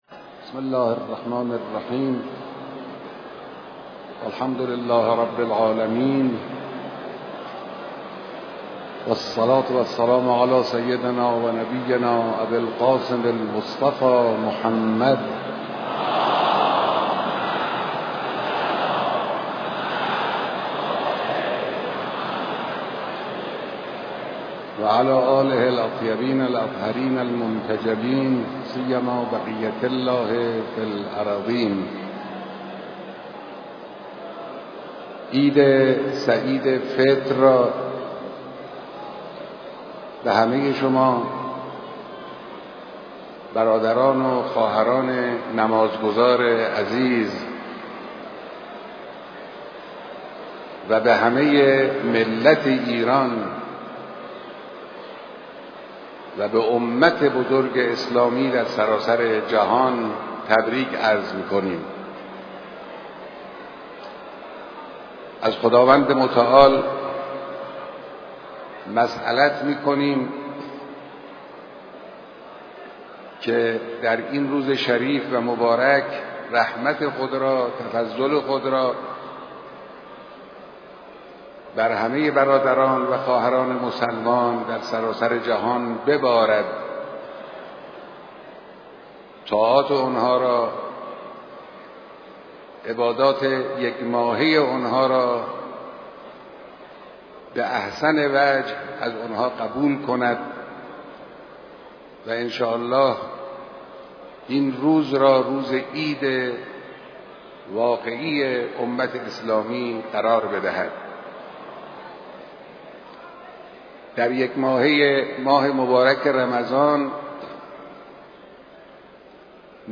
خطبه‌هاى نماز عيد سعيد فطر